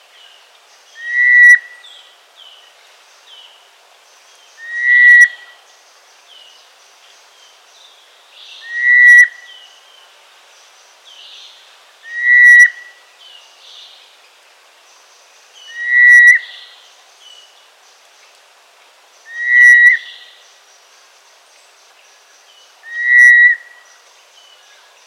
We only found this out after an outing to the reserve where we had planned to record a soundscape in the forest.
Northern Forest Rain Frog
Throughout the morning we regularly picked up a loud, rather penetrating, chirp in the headphones, and it always seemed to be coming from fairly high up in the surrounding trees.
Both confirmed that it is very difficult to pinpoint this family of frogs when calling because of their habit of ‘megaphone’ calling while hidden away in the leaf litter or from an elevated spot on a rock and clump of vegetation and ‘throwing’ the sound up into the tree line.
Northern-Forest-Rain-Frog.mp3